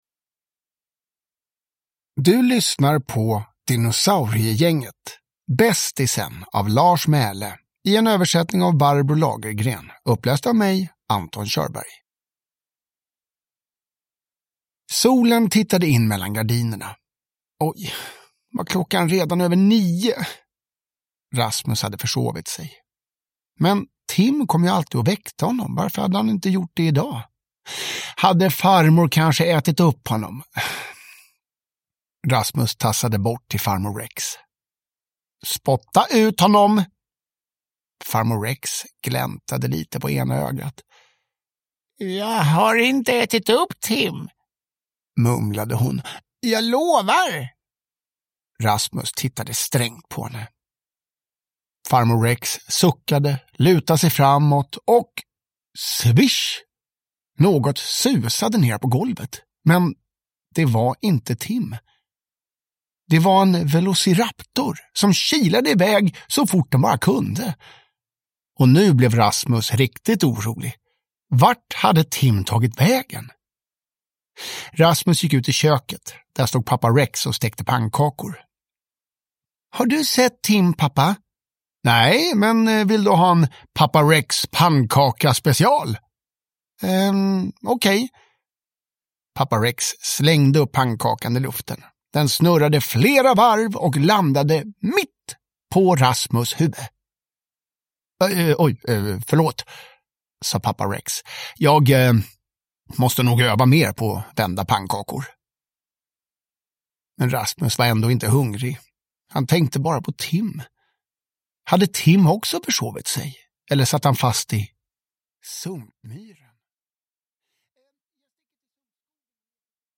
Bästisen (ljudbok) av Lars Mæhle